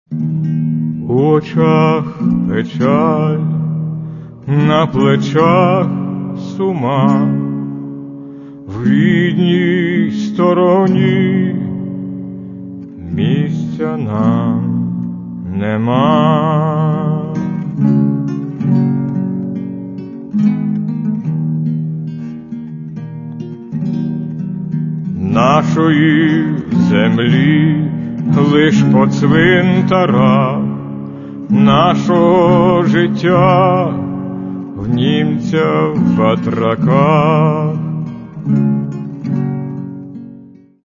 Каталог -> Народна -> Бандура, кобза тощо